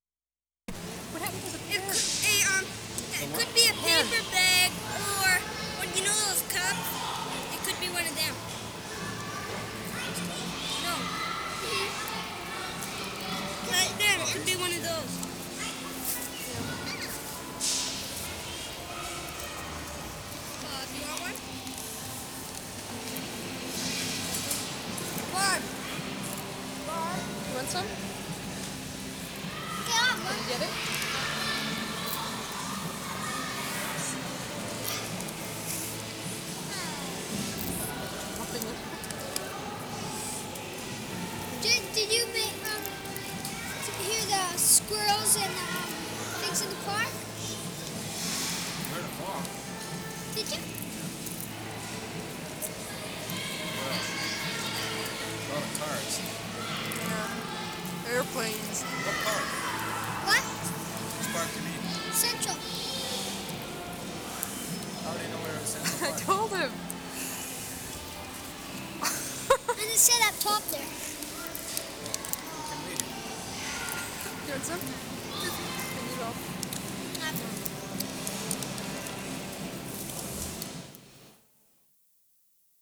CENTRAL PARK / BURNABY ICE RINK March 22, 1973
6. More kids around microphones.